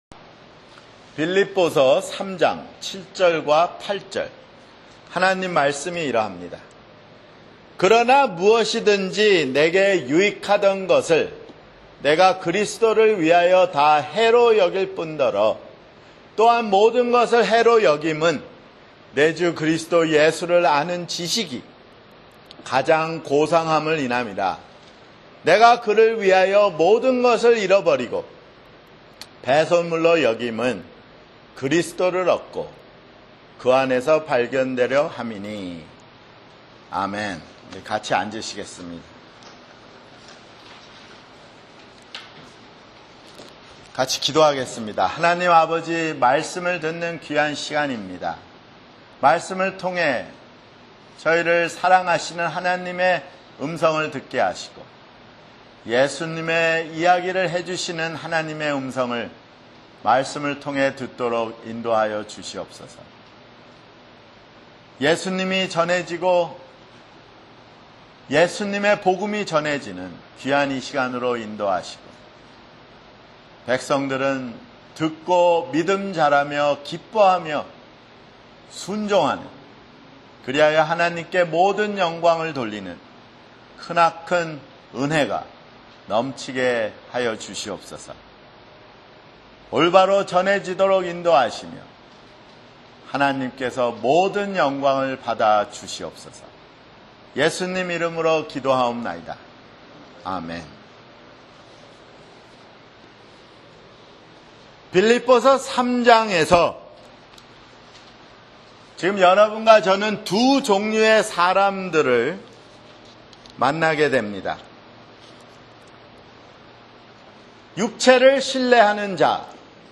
[주일설교] 빌립보서 (41)